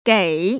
[gěi]
게이